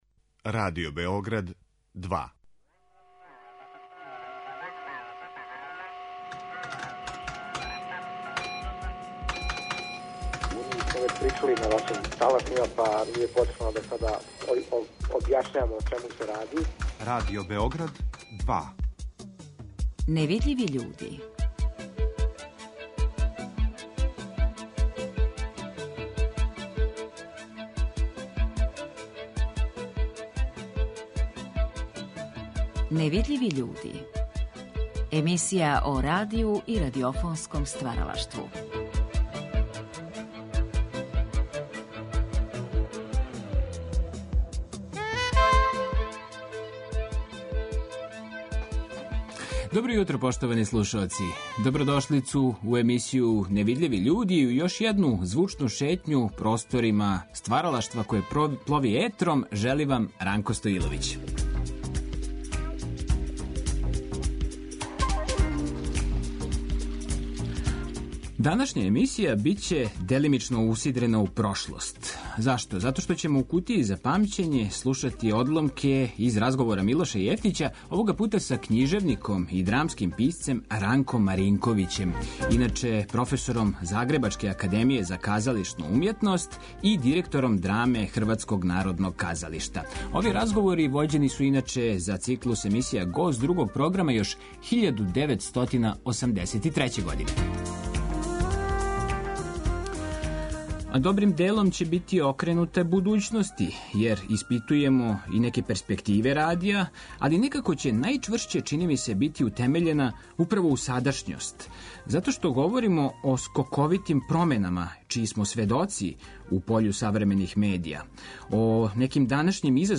Уживо из студија Радио Новог Сада, са нама ће поделити своја размишљања о скоковитим променама у пољу савремених медија, о данашњим изазовима професије новинара, о моћима и опасностима новинарског позива, о предностима, ограничењима, типовима и шансама данашњег радија...